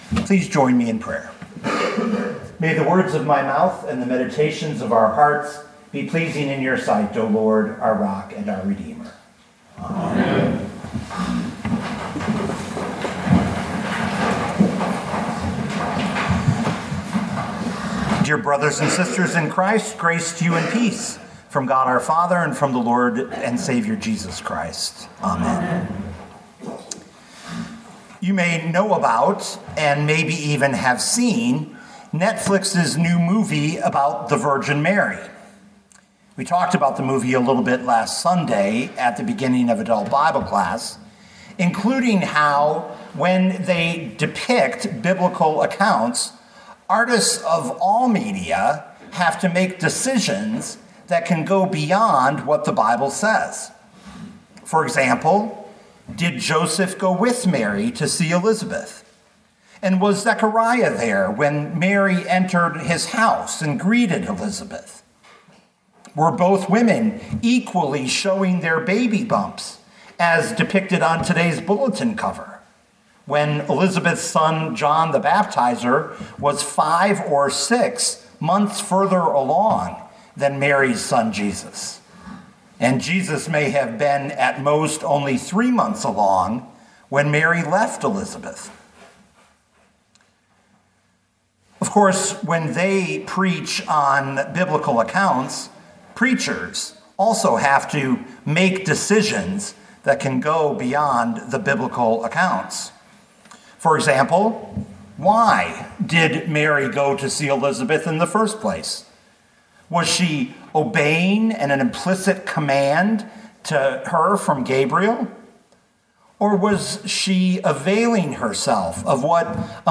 2024 Luke 1:39-56 Listen to the sermon with the player below, or, download the audio.